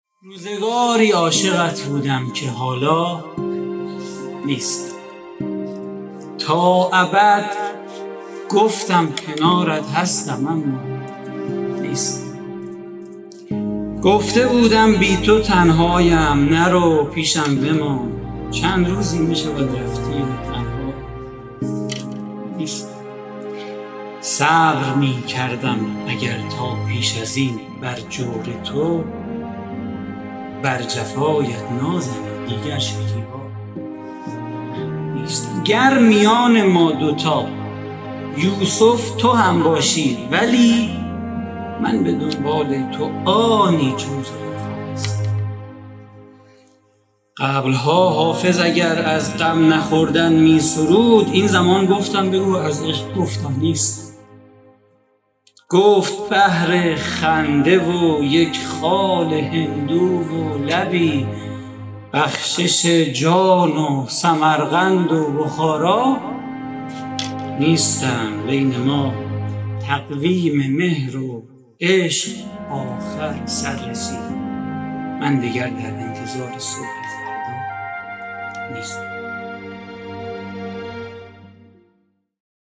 اجرا در موسسه شهرستان ادب